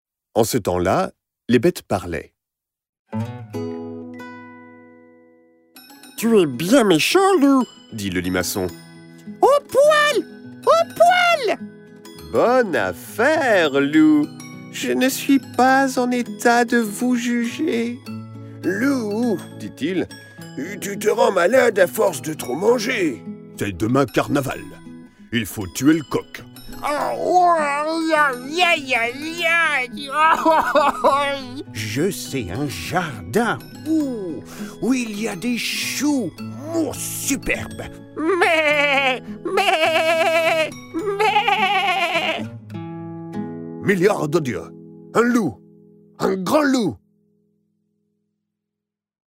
Click for an excerpt - 10 histoires de loup de Jean-françois Bladé